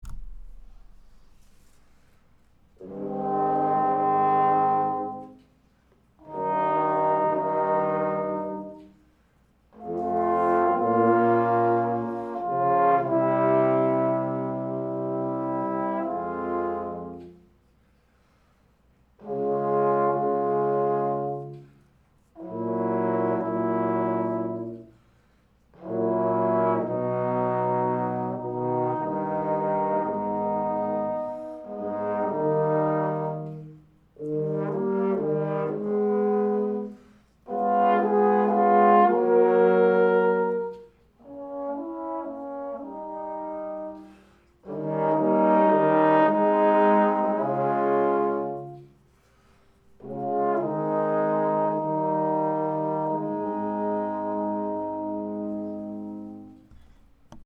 First, print out this PDF – it’s the score to the third movement of the Three Equali trombone quartet by Beethoven…
8. First part played on a small mouthpiece, second part played with cup mute, third part played on a small bore student model instrument and fourth part played with bucket mute